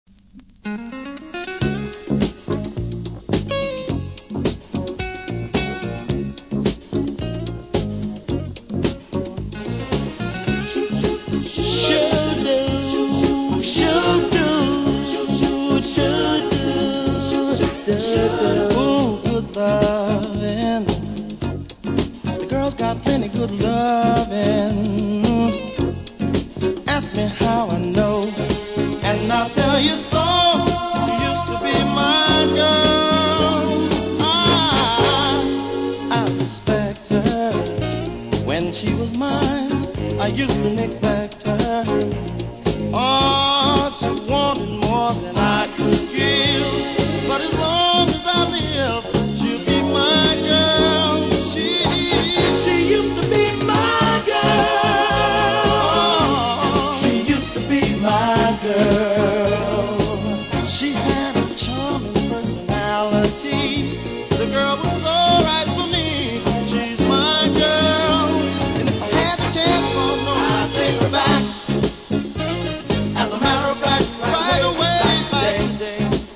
Vinyl
多少キズありますが音には影響せず良好です。